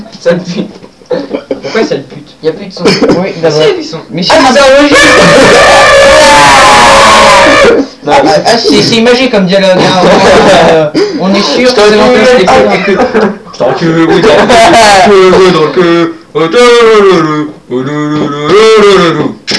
Interview difficile avec de tout dedans (et meme du reste)